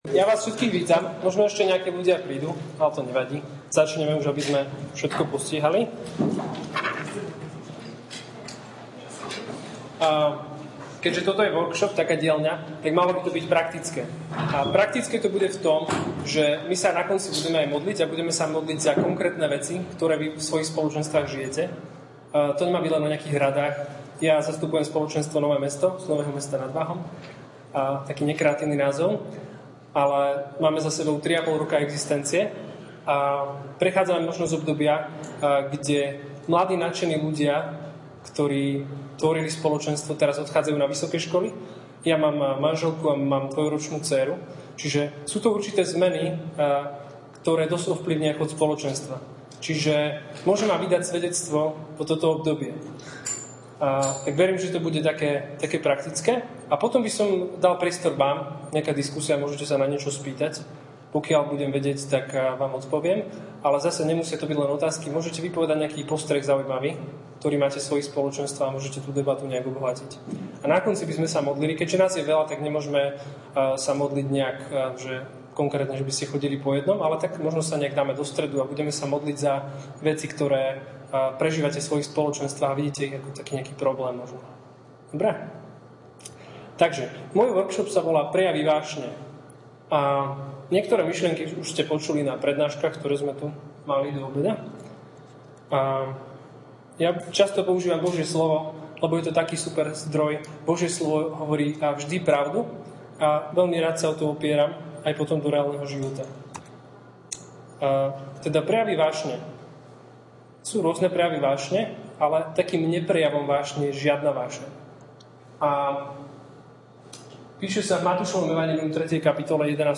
Audio prednášky